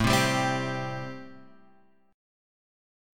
Am7 chord {5 3 5 5 x 3} chord